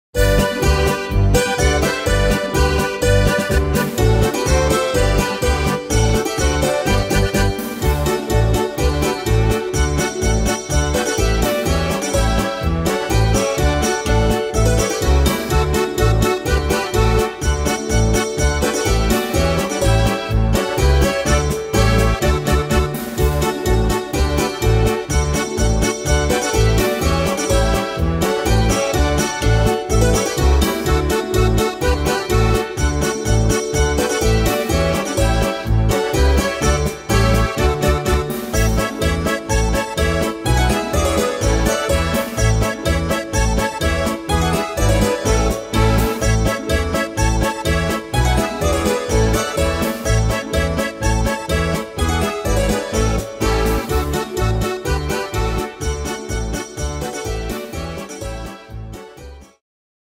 Tempo: 125 / Tonart: G-Dur